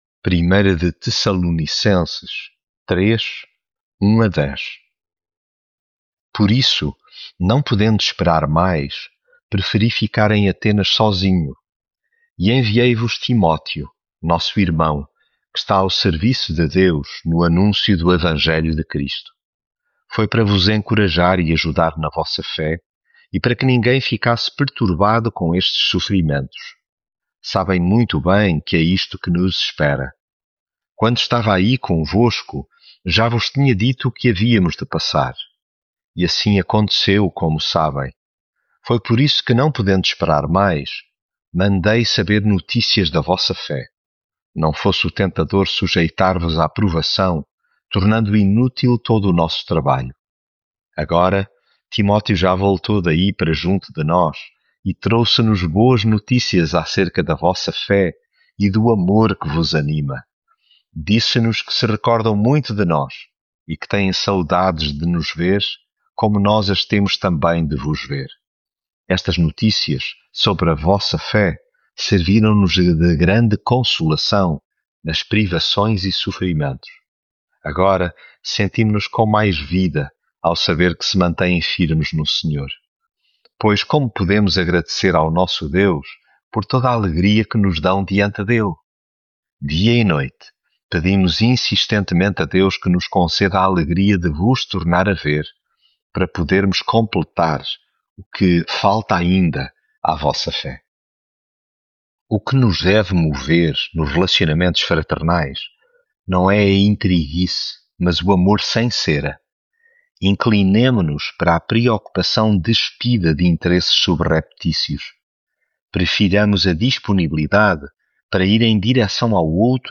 Devocional Evangelho de João Leitura Bíblica Depois disto, Jesus passou para a região da Galileia.